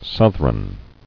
[south·ron]